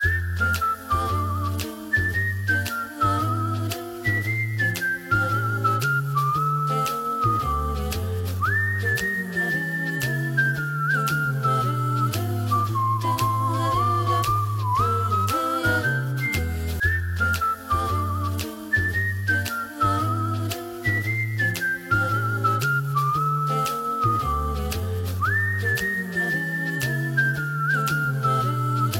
Whistle, Android, Meditationsmusik